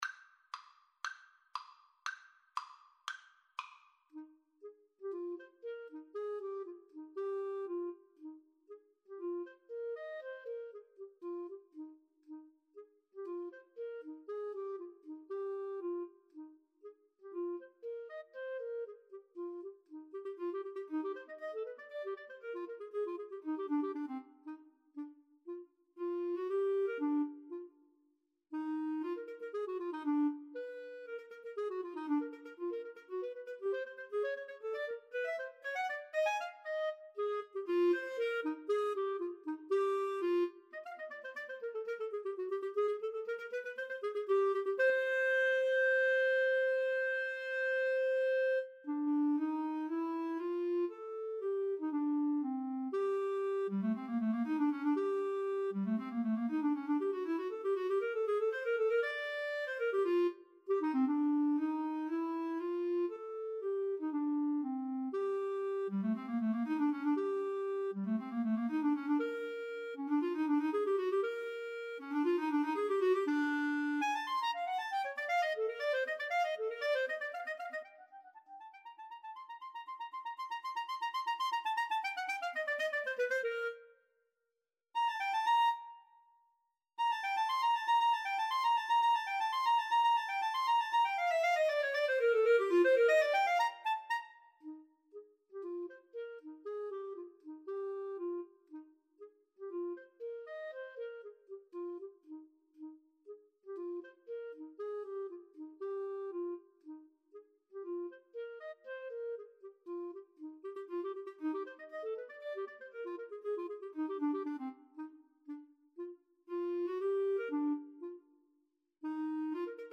Eb major (Sounding Pitch) F major (Clarinet in Bb) (View more Eb major Music for Clarinet Duet )
Allegro giusto = 118 (View more music marked Allegro)
Classical (View more Classical Clarinet Duet Music)